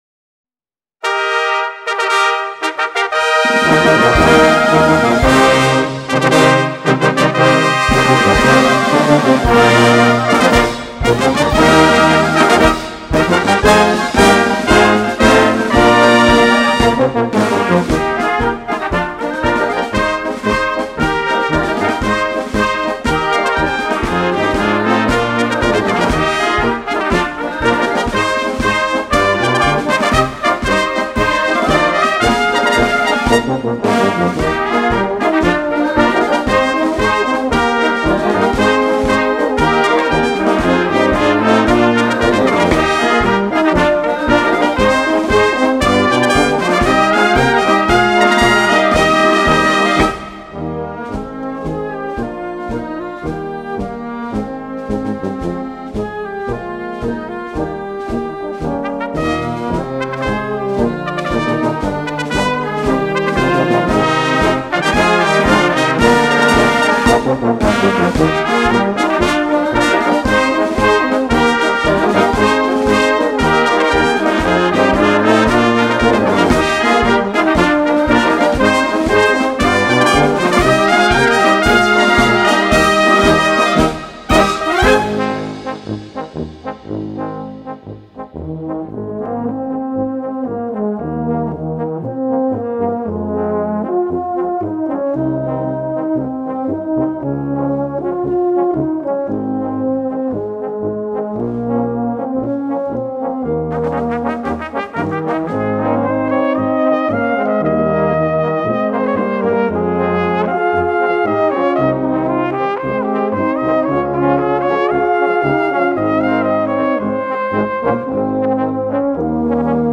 Konzertmarsch für Blasorchester
Besetzung: Blasorchester